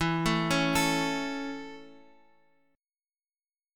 E 6th Suspended 4th